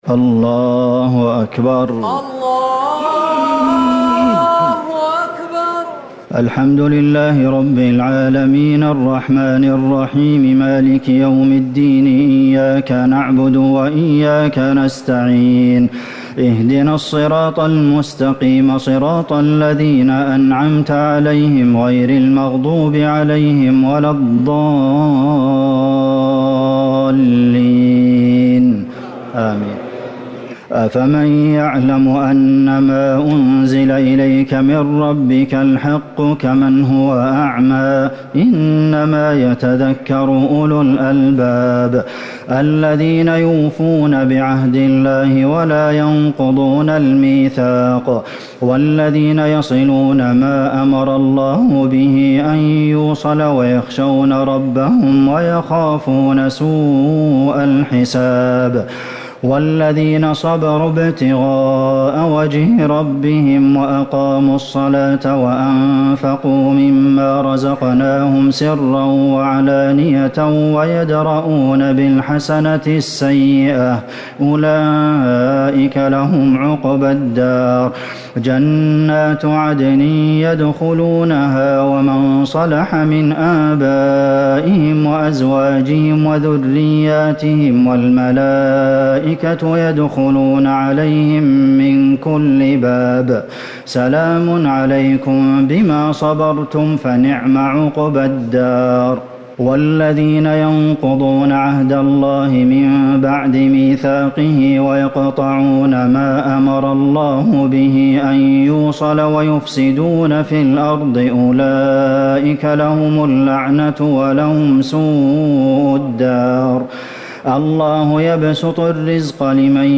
ليلة ١٢ رمضان ١٤٤٠ سورة الرعد ١٩ - إبراهيم ٢٧ > تراويح الحرم النبوي عام 1440 🕌 > التراويح - تلاوات الحرمين